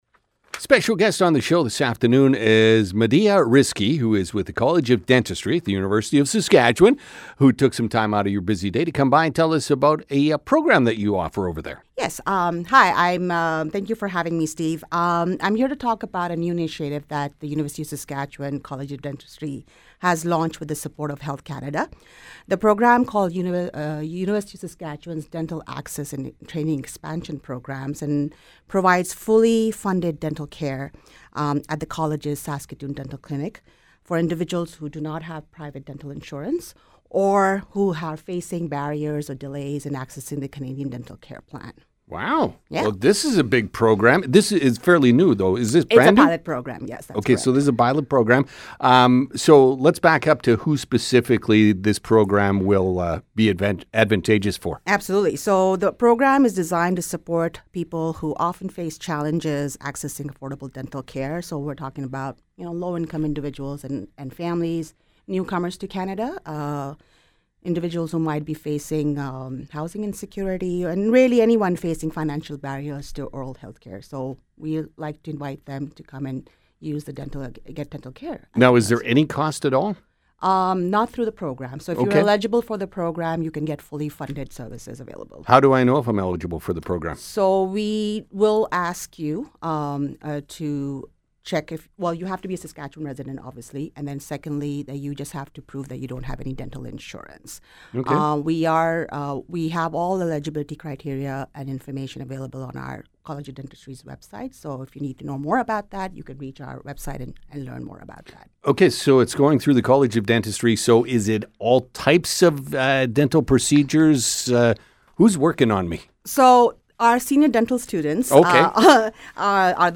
usask-dental-program-interview.mp3